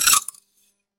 Preview and download these AI-generated food & drink sounds.
Crunchy Bite
A satisfying crunchy bite into a crisp apple or fresh vegetable
crunchy-bite.mp3